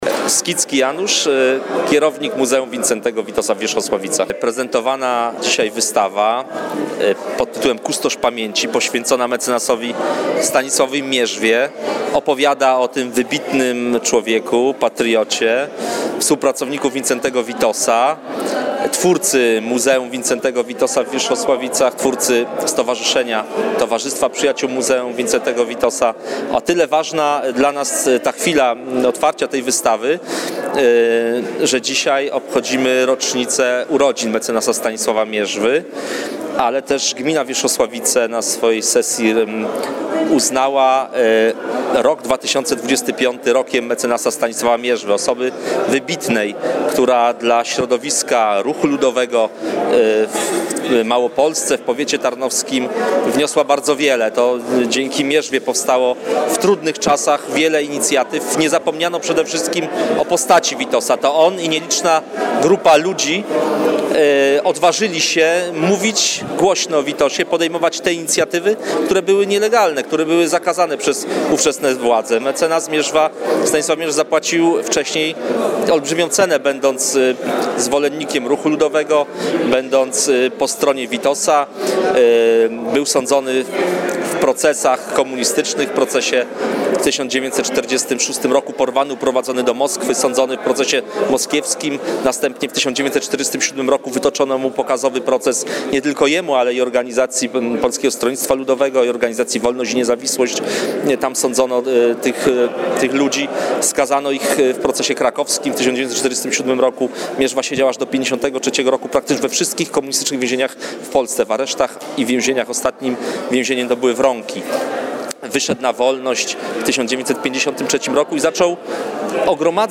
Podczas sobotnich uroczystości rozmawialiśmy w Wierzchosławicach